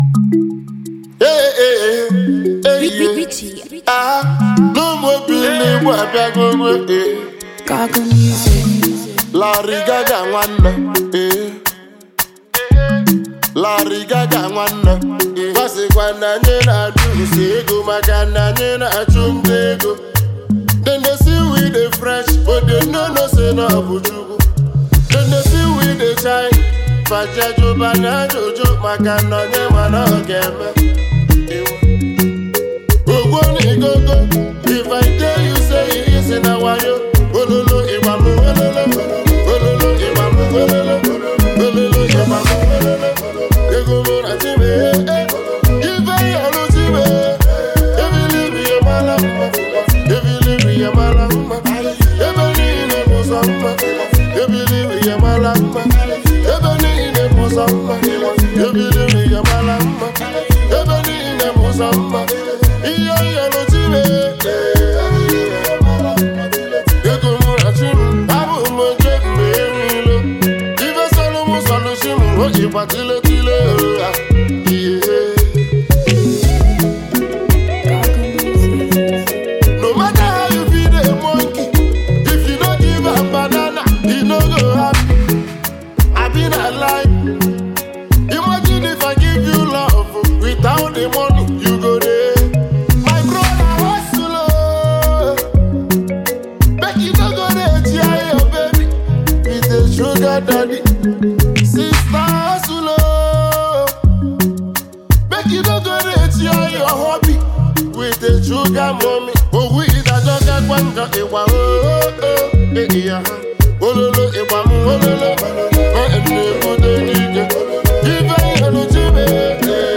featuring highlife music duo